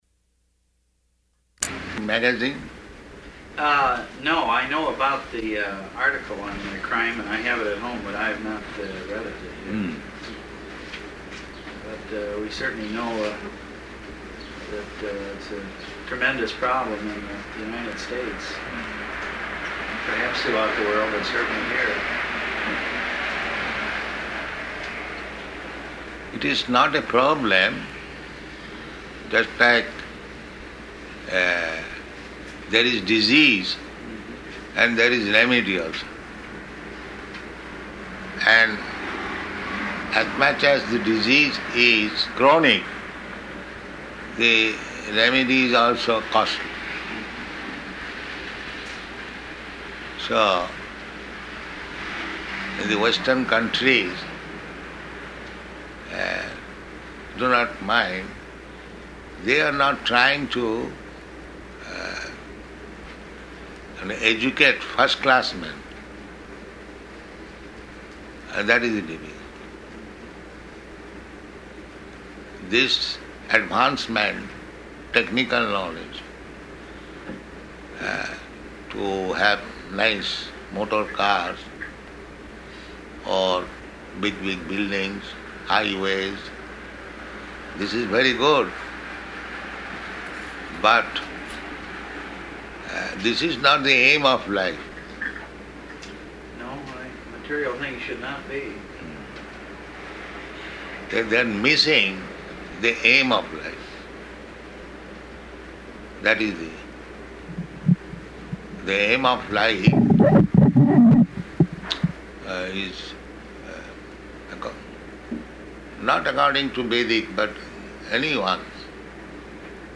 Room Conversation with the Mayor of Evanston --:-- --:-- Type: Conversation Dated: July 4th 1975 Location: Chicago Audio file: 750704R1.CHI.mp3 Prabhupāda: ...magazine?